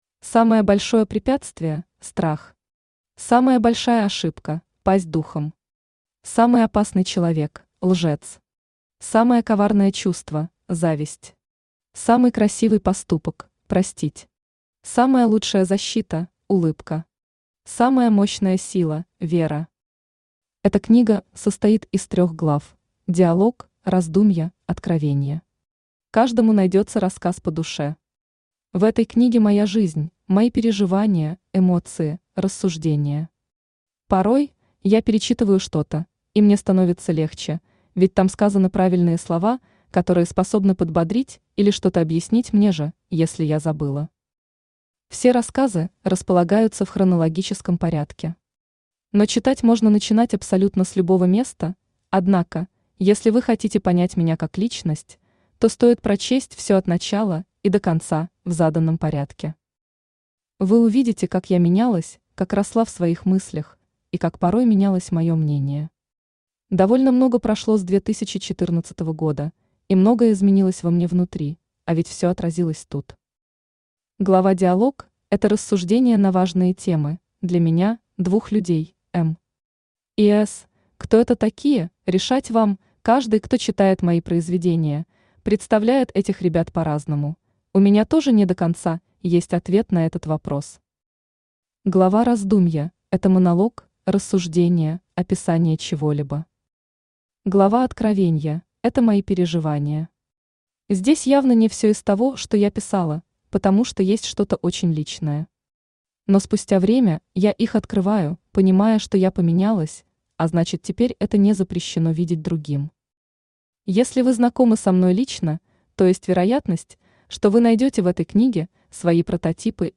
Аудиокнига Найди себя | Библиотека аудиокниг
Aудиокнига Найди себя Автор Мария Виктровна Степанова Читает аудиокнигу Авточтец ЛитРес.